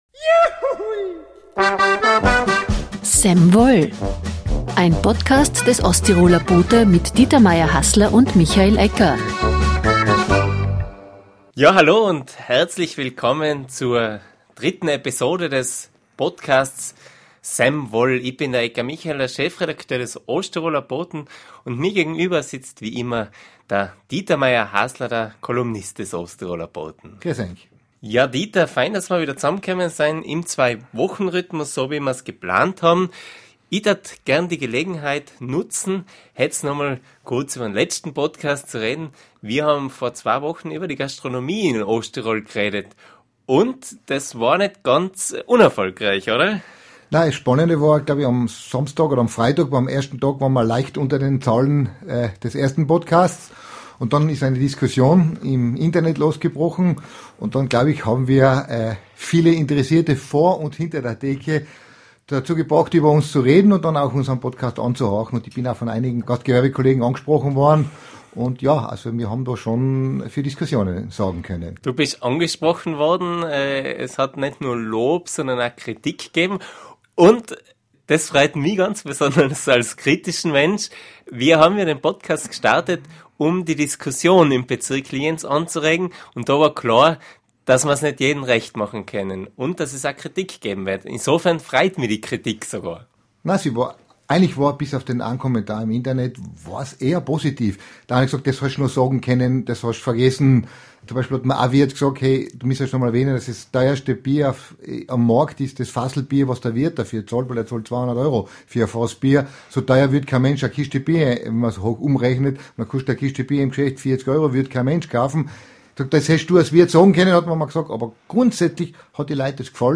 Der Stau, den die Ampel in Sillian am vergangenen Wochenende ausgelöst hatte, ärgerte viele Verkehrsteilnehmer. Ein Gespräch über den Verkehr in Sillian.